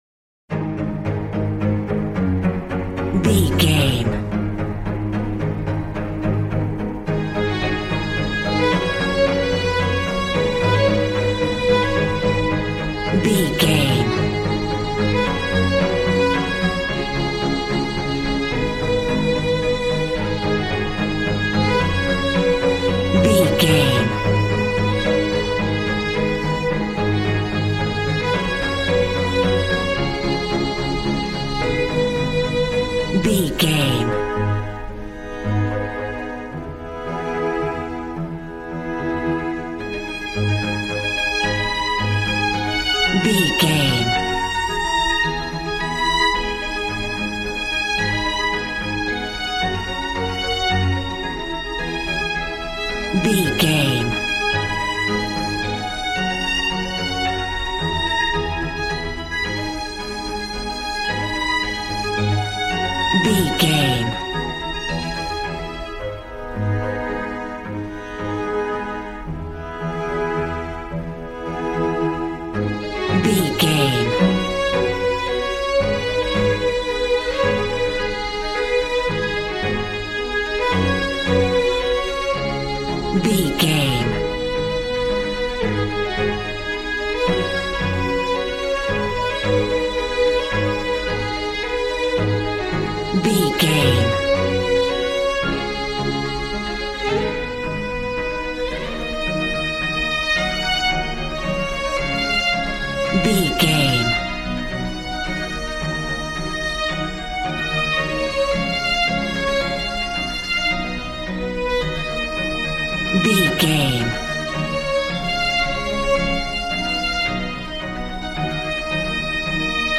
Aeolian/Minor
A♭
regal
cello
violin
brass